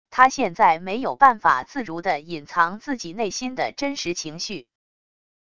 他现在没有办法自如地隐藏自己内心的真实情绪wav音频生成系统WAV Audio Player